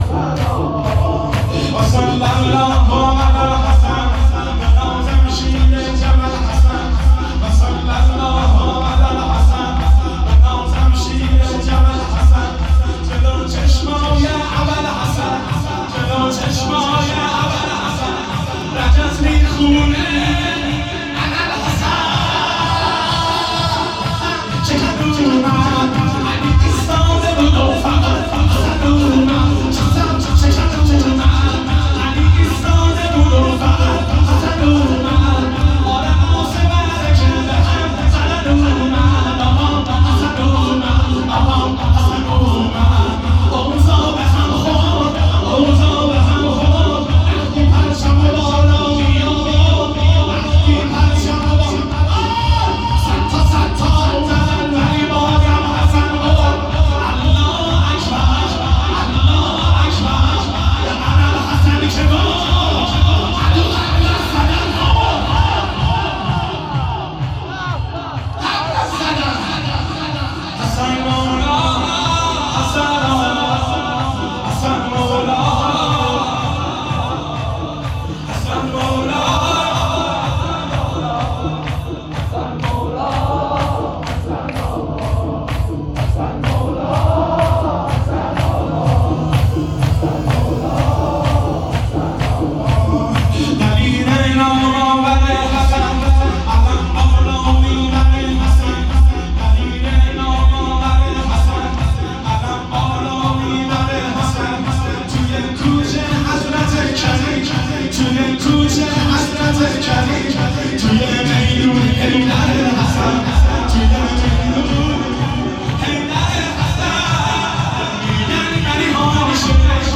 شور شب 29 صفرالمظفر 1402